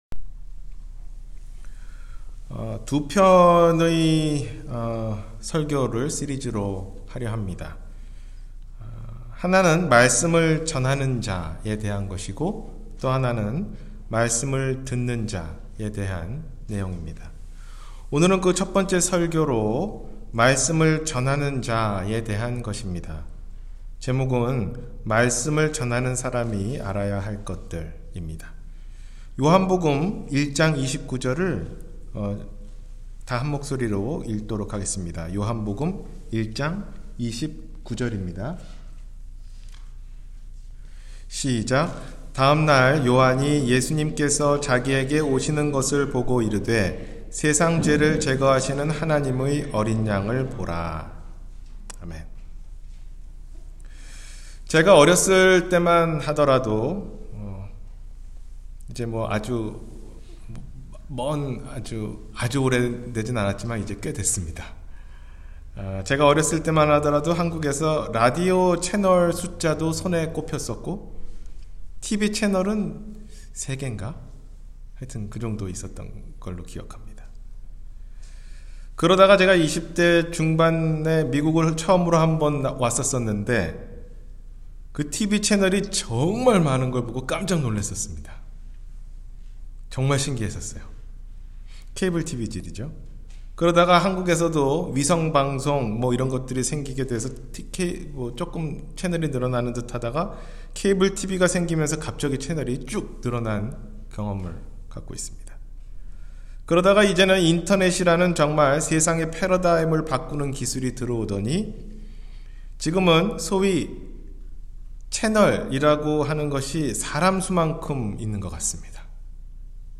말씀을 전하는 사람이 알아야할 것 – 주일설교